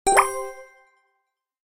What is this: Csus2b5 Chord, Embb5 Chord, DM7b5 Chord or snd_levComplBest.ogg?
snd_levComplBest.ogg